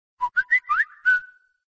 Kategorien: Sms Töne